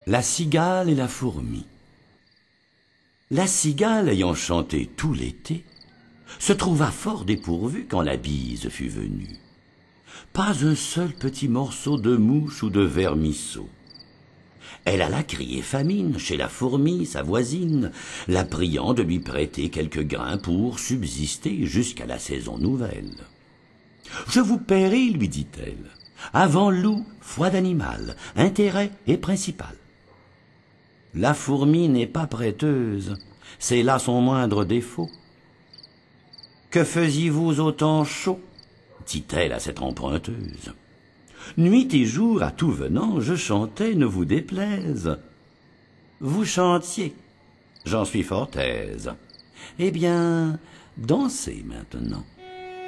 Lire un extrait Jean De La Fontaine Fables - Tome 1 Coffragants Date de publication : 2009 16 fables dites par Albert Millaire.